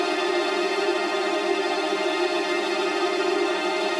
Index of /musicradar/gangster-sting-samples/Chord Loops
GS_TremString-F7.wav